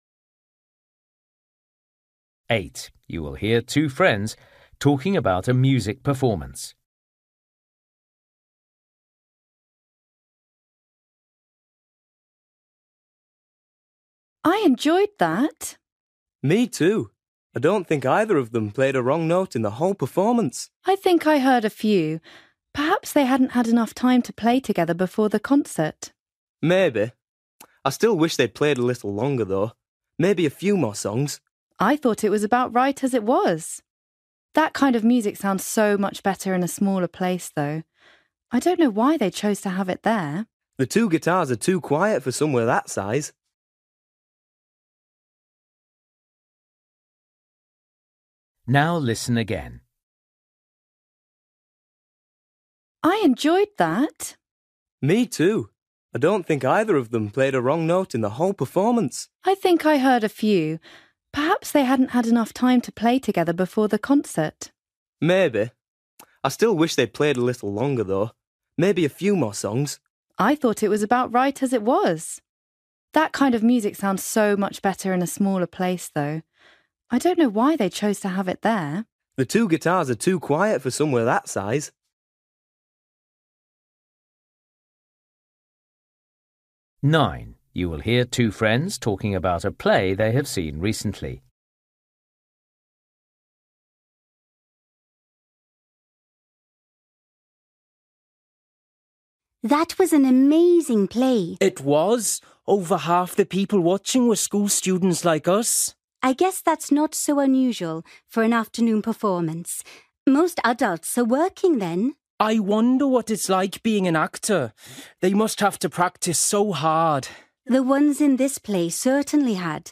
Listening: everyday short conversations
8   You will hear two friends talking about a music performance. The friends agree that
10   You will hear a boy telling a friend about an art course he went on. What did the boy enjoy most about it?